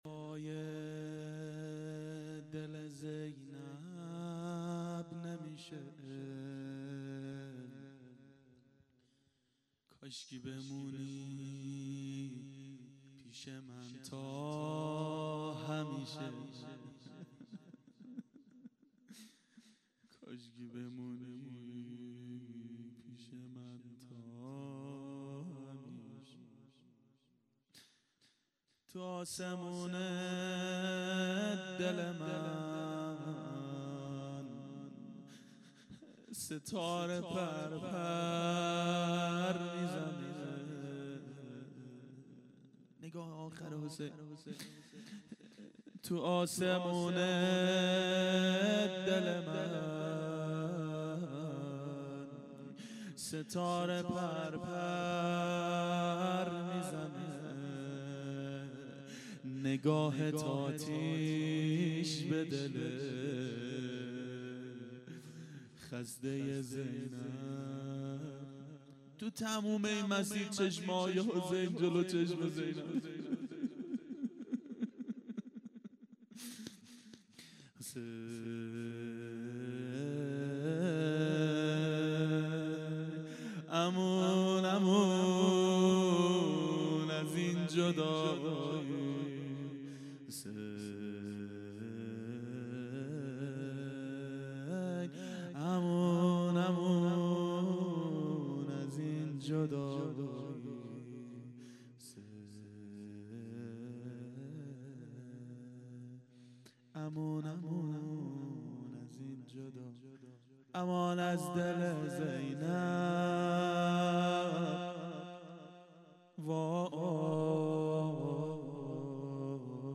دهه اول صفر سال 1392 هیئت شیفتگان حضرت رقیه سلام الله علیها
01-گریه-دوای-دل-زینب-نمیشه-و-روضه.mp3